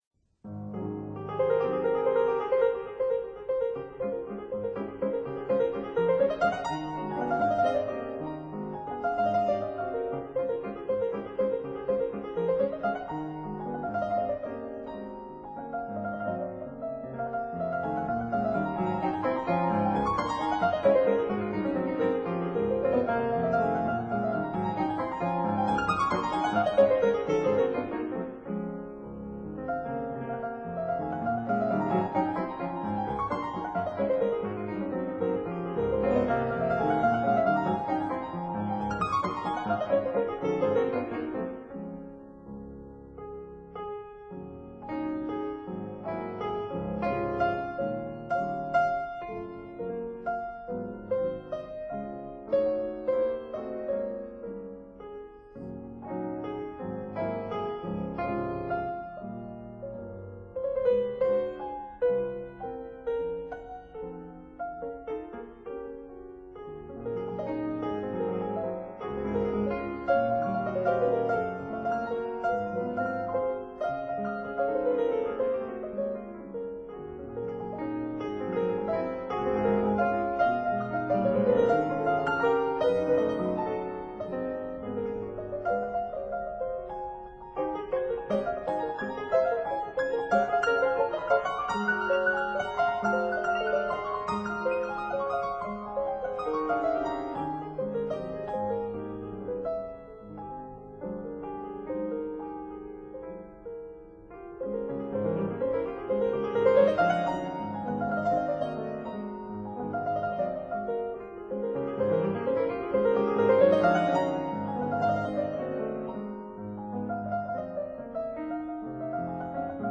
모짜르트 피아노 소나타 No2  쾨헬 280 1악장 알레그로